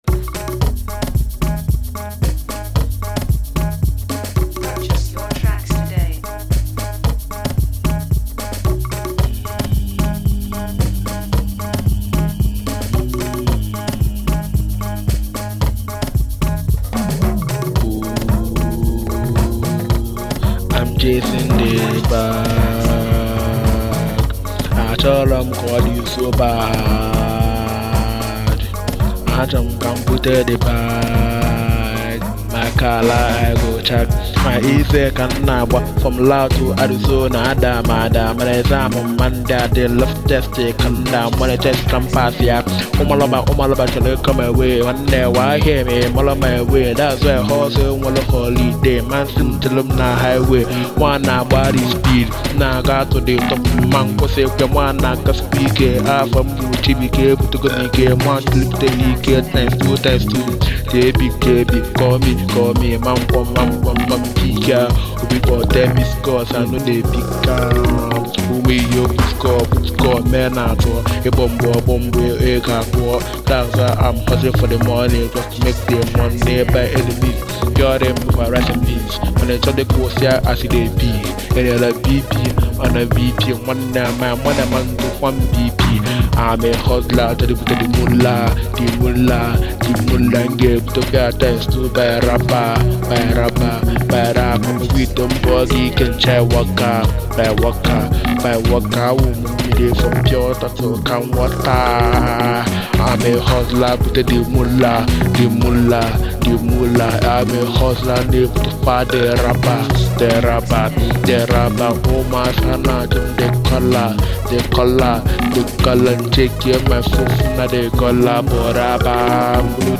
Am a Nigerian upcoming Igbo rapper